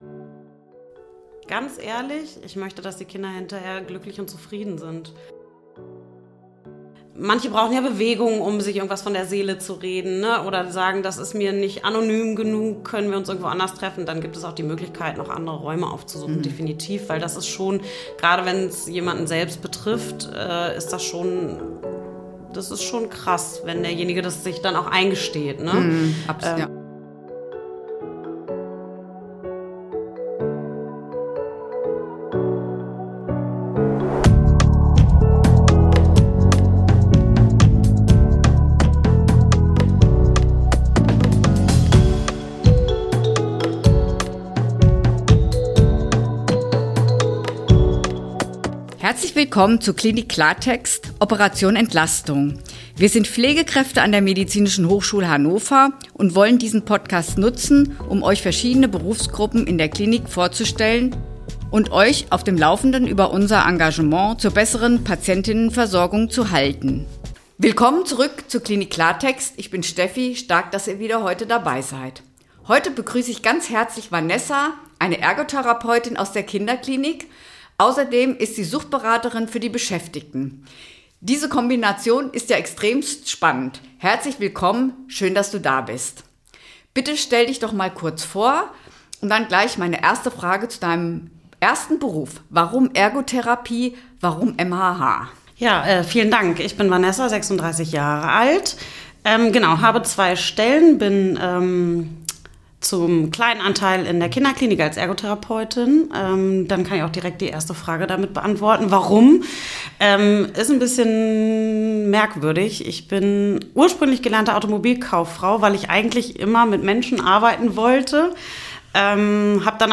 Ergotherapie & Suchtberatung – Ein Interview